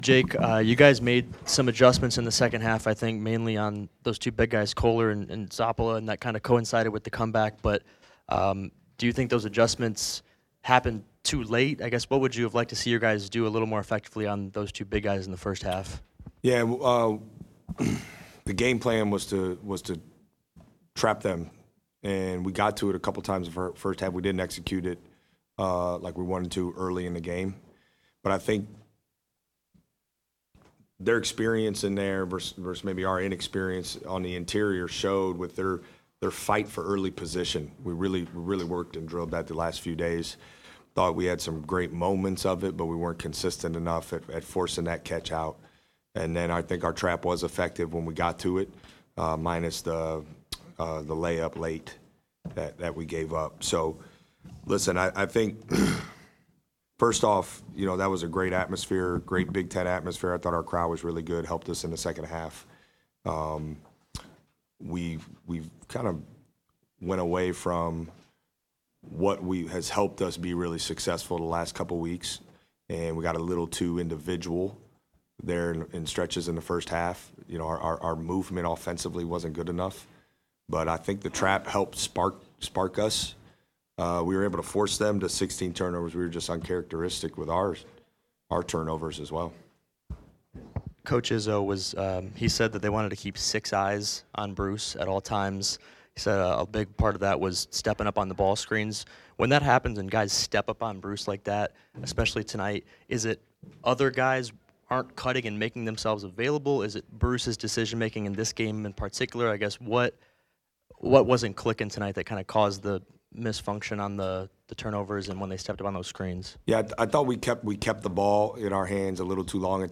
Press Conference after loss to #18 Michigan State